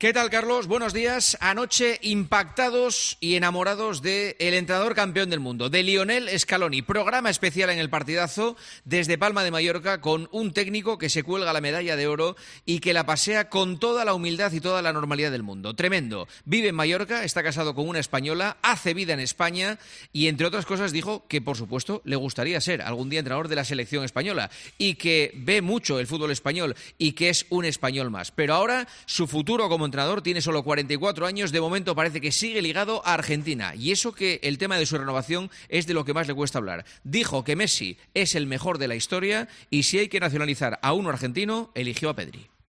El comentario de Juanma Castaño
El presentador de 'El Partidazo de COPE' analiza la actualidad deportiva en 'Herrera en COPE'